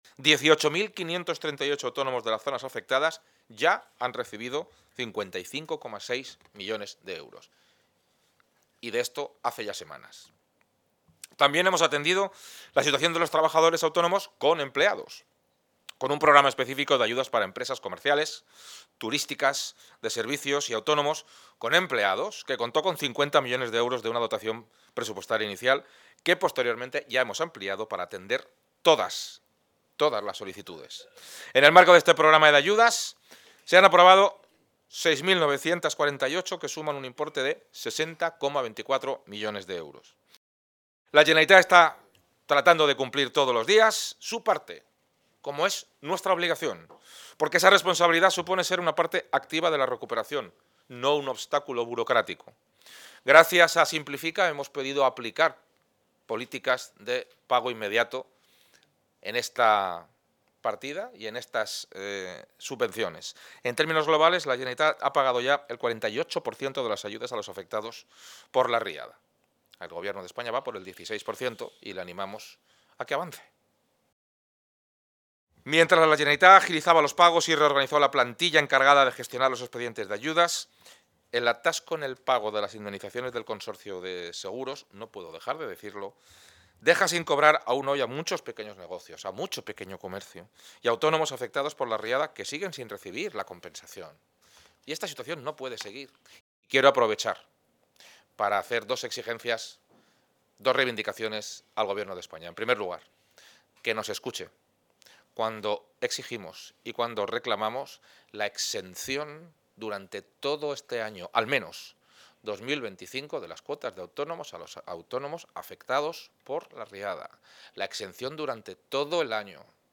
Así se ha manifestado durante la Asamblea de la Asociación de Trabajadores (ATA) de la Comunidad Valenciana